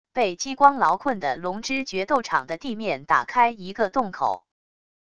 被激光牢困的笼之角斗场的地面打开一个洞口wav音频